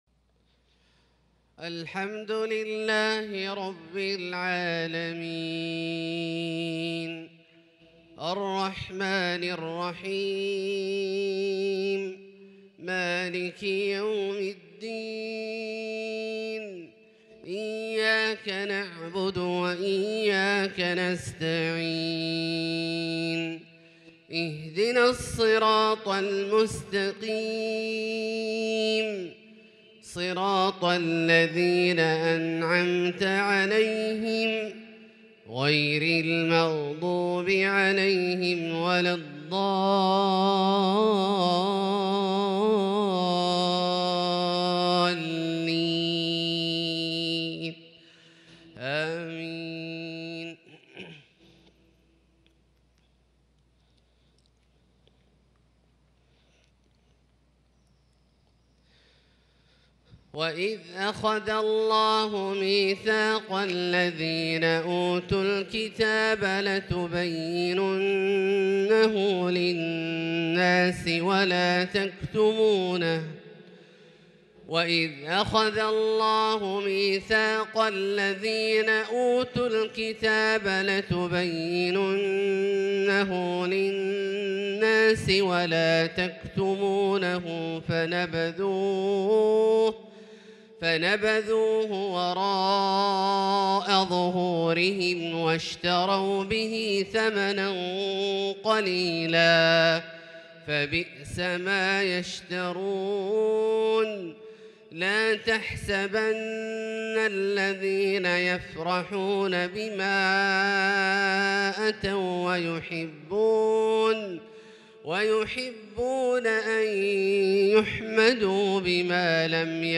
صلاة الفجر للقارئ عبدالله الجهني 9 ذو الحجة 1442 هـ
تِلَاوَات الْحَرَمَيْن .